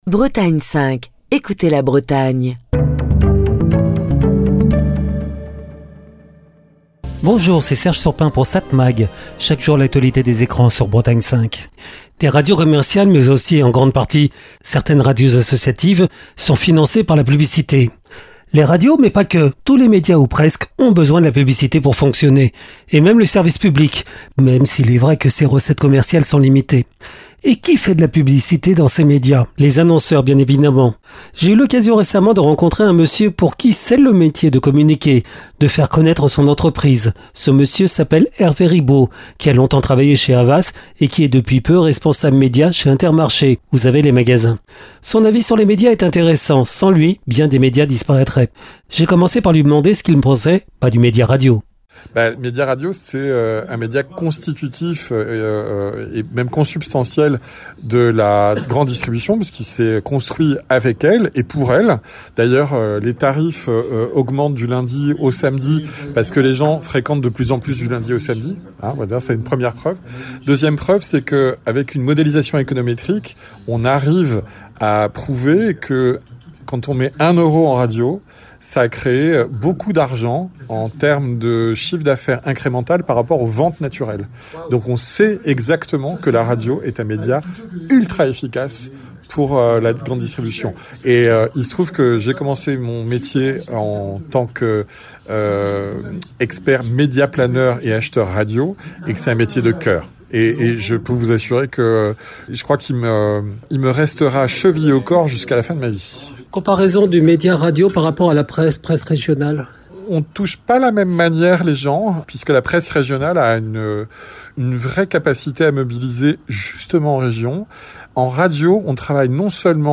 Chronique du 17 novembre 2025.